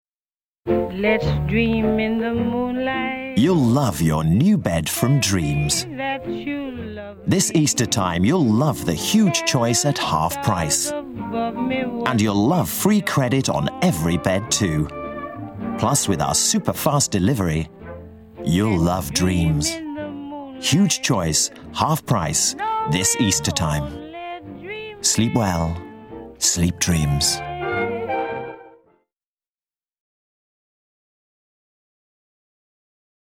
Soft sell ads
Friendly and sincere middle aged male voiceover for Dreams Beds